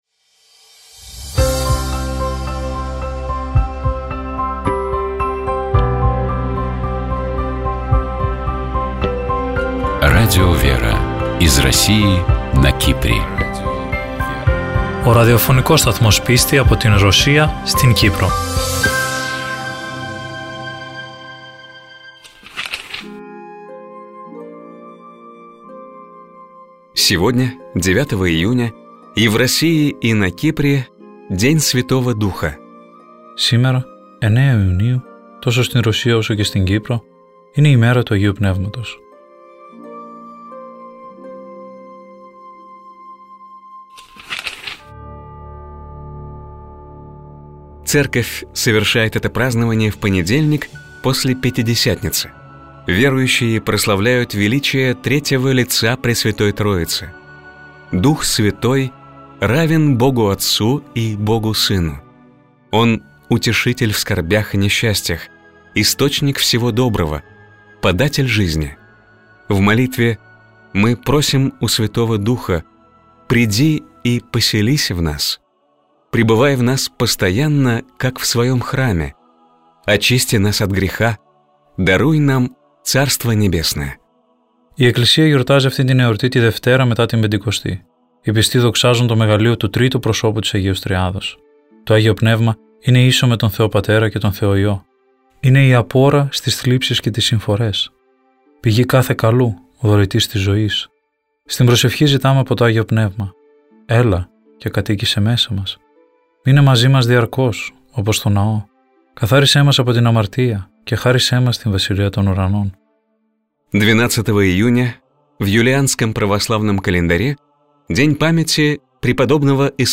По благословению митрополита Лимассольского Афанасия (Кипрская Православная Церковь) в эфире радио Лимассольской митрополии начали выходить программы Радио ВЕРА. Популярные у российского слушателя программы переводятся на греческий язык и озвучиваются в студии Радио ВЕРА: «Православный календарь», «Евангелие день за днем», «Мудрость святой Руси», «ПроСтранствия», «Частное мнение» и другие.